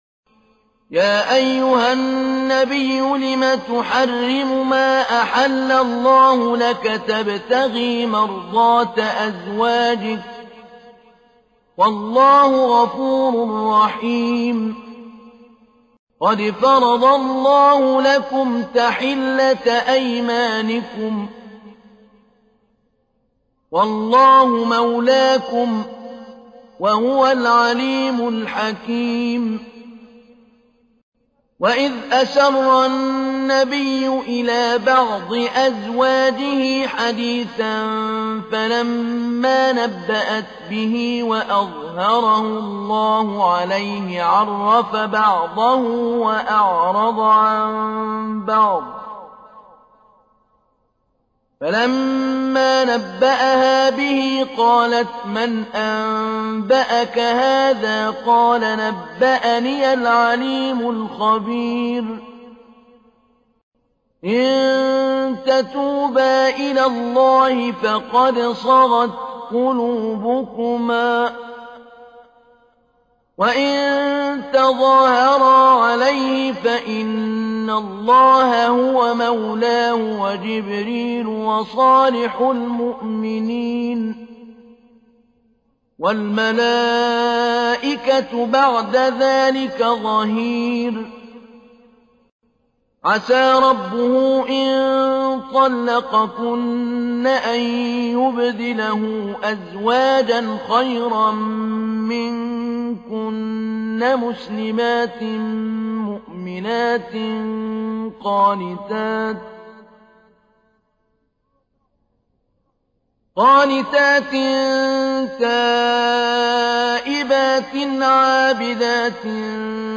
تحميل : 66. سورة التحريم / القارئ محمود علي البنا / القرآن الكريم / موقع يا حسين